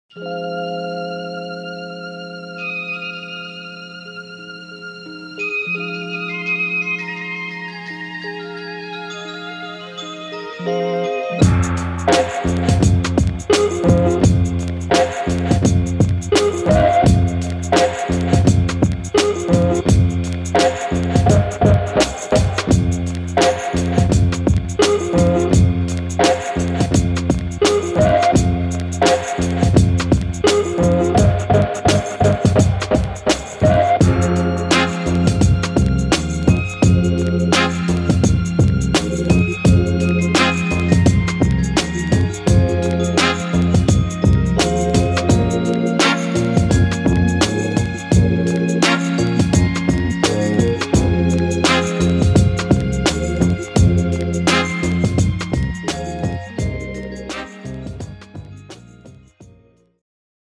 keys, drums, vocals
guitar
bass